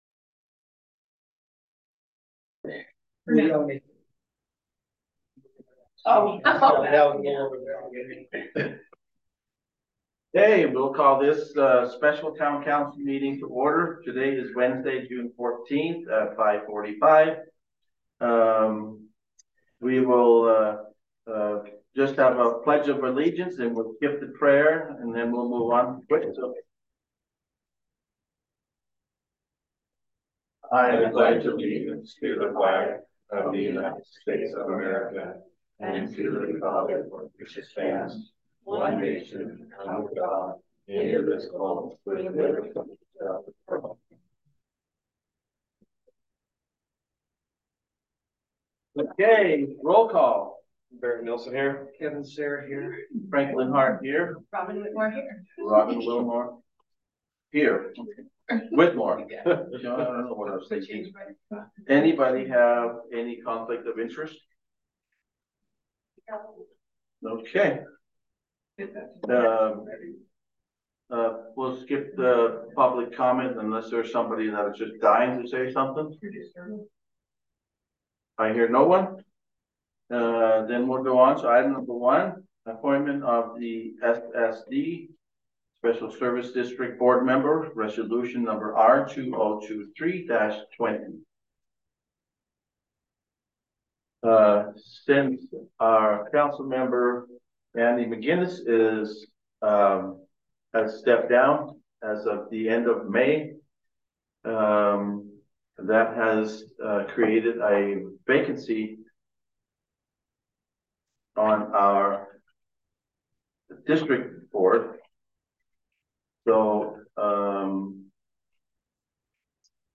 Special Town Council Meeting
In accordance with state statute, one or more council members may be connected via speakerphone.